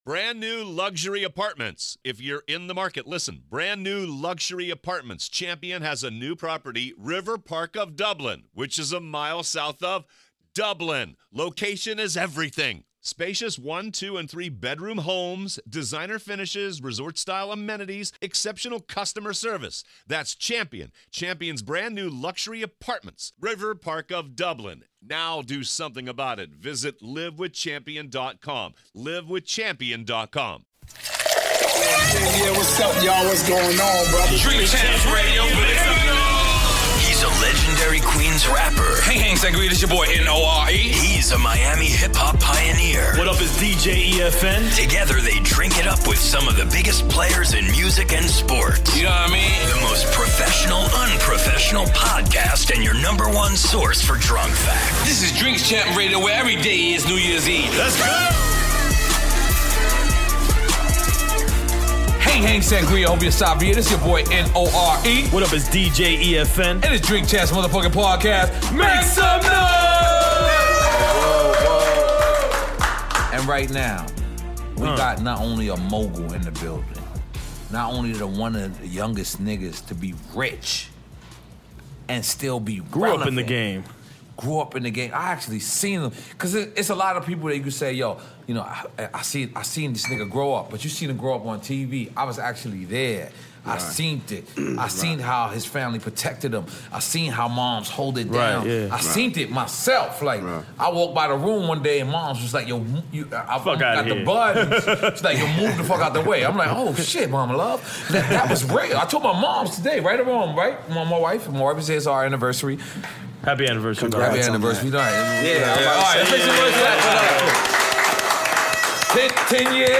In this episode the guys drink it up with Bow Wow. The guys discuss Bow Wow's career, Cash Money, his beginnings with Snoop, Death Row, Jermaine Dupri and a lot more.